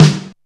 M SNARE 1.wav